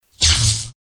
lightning.mp3